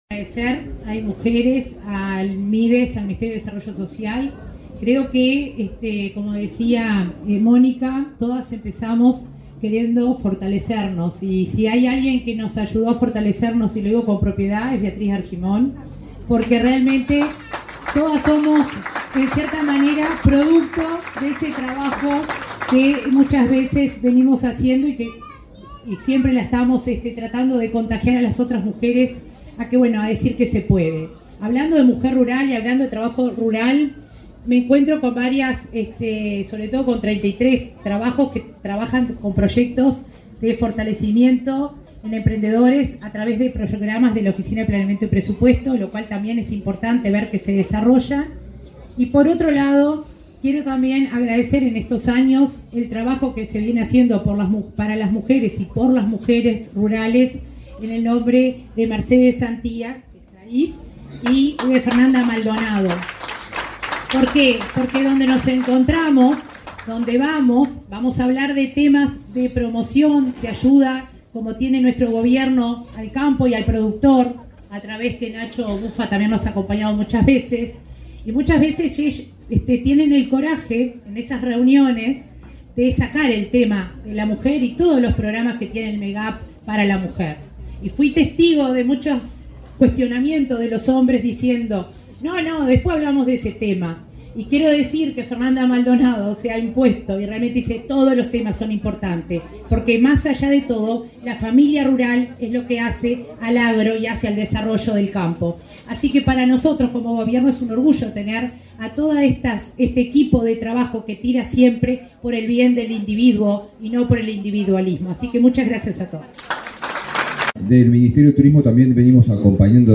Palabras de autoridades en la Expo Prado